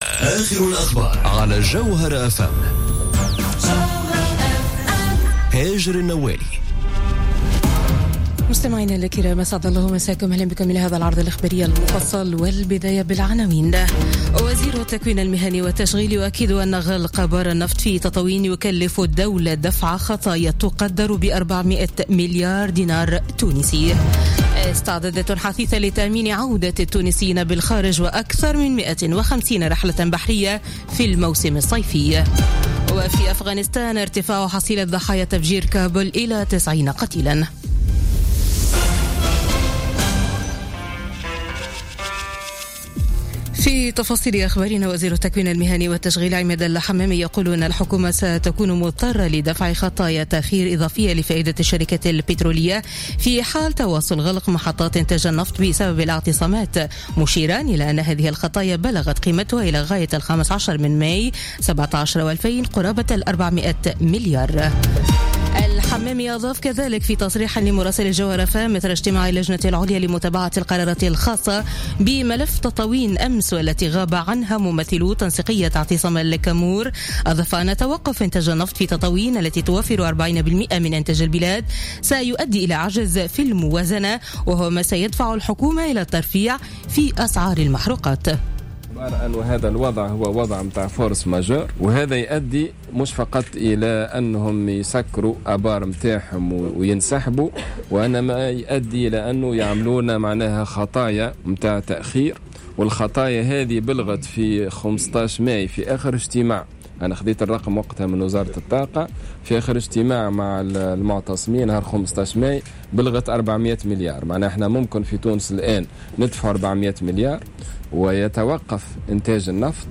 نشرة أخبار منتصف الليل ليوم الخميس 1 جوان 2017